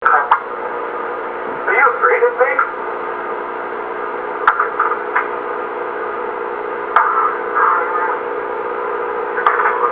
Some E.V.P. captured at St. Mary's Hospital:
EVP_I_am:   A guest who attended our party was sitting the psychic ward.